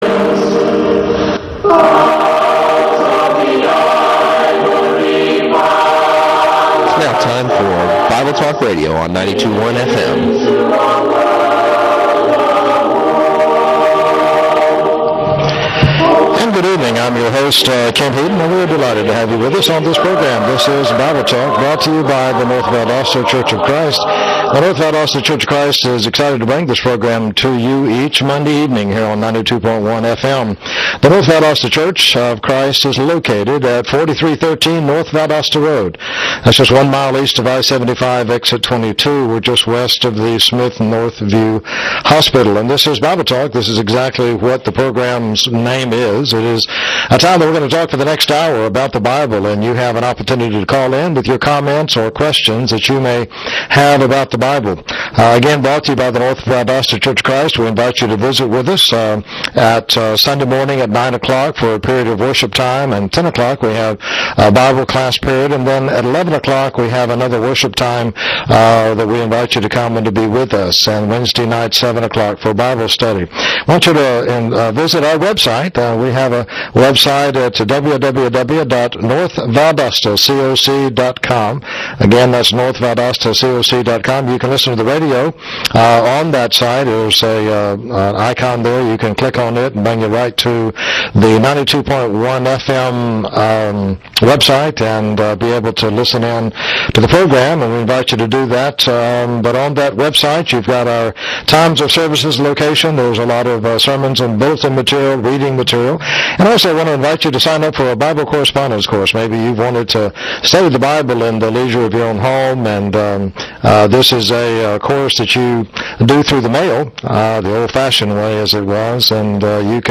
Guest panelist